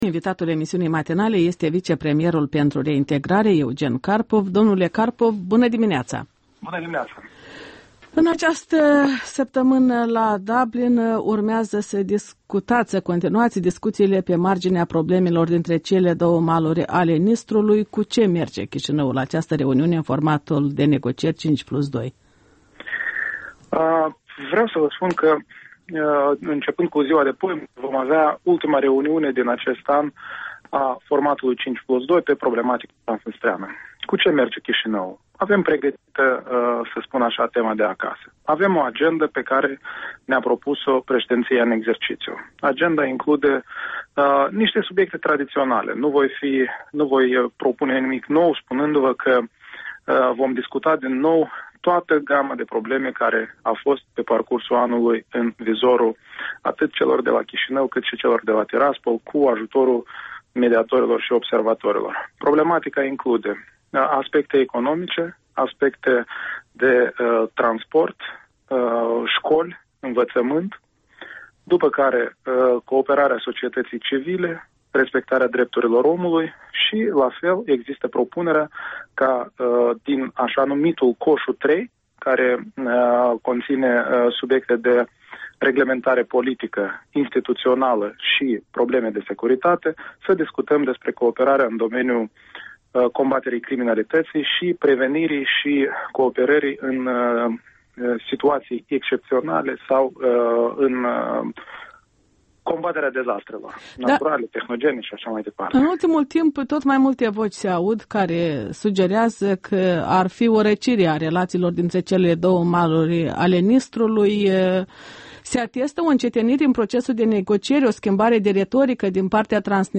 Interviul dimineții: cu vicepremierul Eugen Carpov în ajunul negocierilor 5+2 de la Dublin